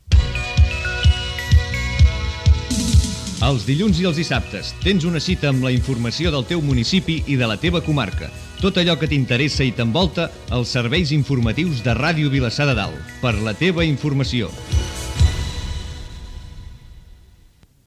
Promoció dels serveis informatius de l'emissora